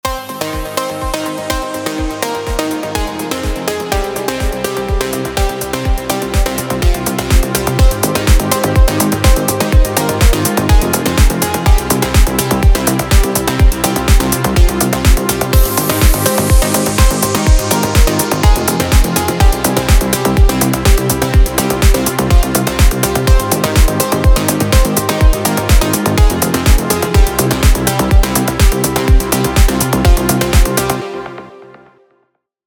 「Hard Pluck Lead」は、弦をはじくような鋭いアタックが特徴のリードサウンドです。
▼Hard Pluck Lead サウンドサンプル
音の立ち上がりが瞬間的で、シャープな音質が印象的ですね。
Hard-Pluck-Lead2.mp3